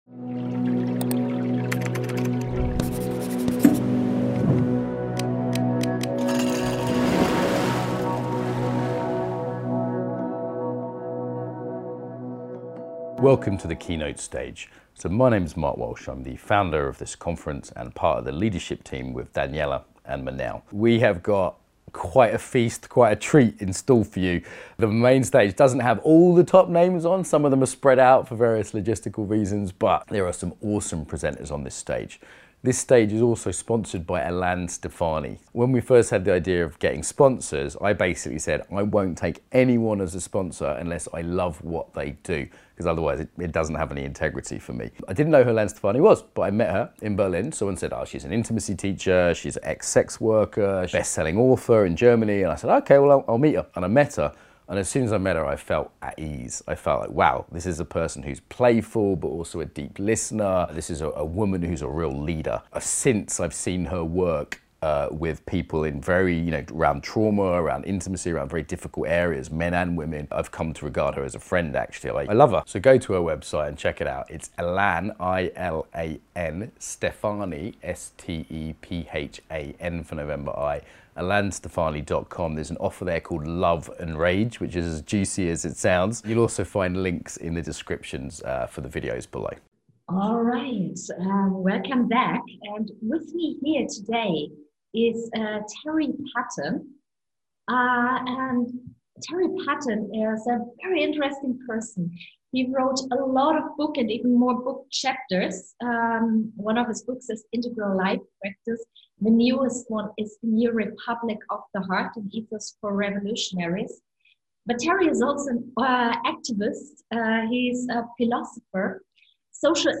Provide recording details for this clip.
This will be an experiential, ceremonial session.